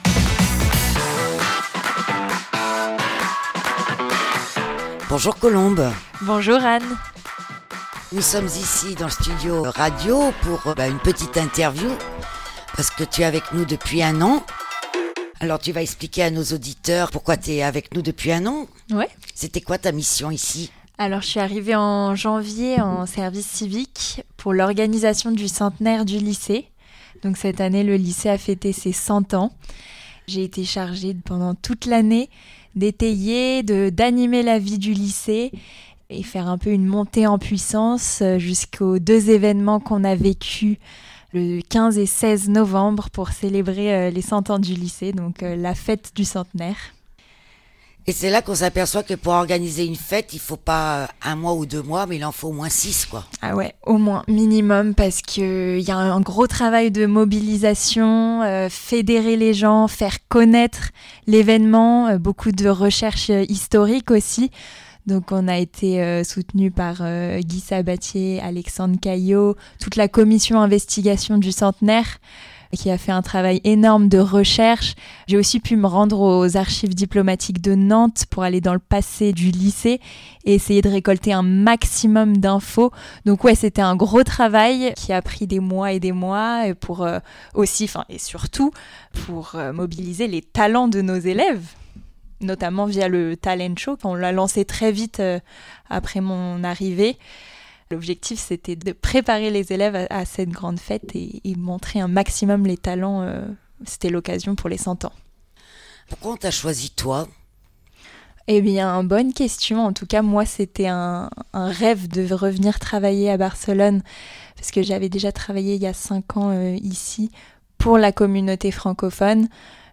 Conférence - Débat - Littérature - Culture = Une invité au micro de la Web Radio.